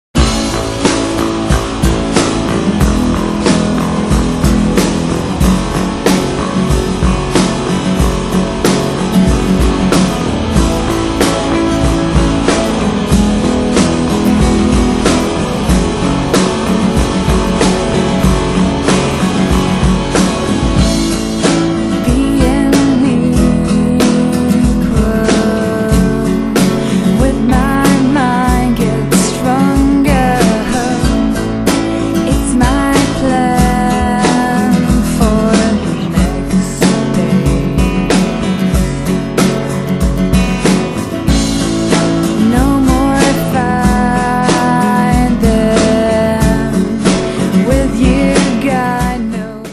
vocal
guitars
bass guitar
drums
saxophones, flute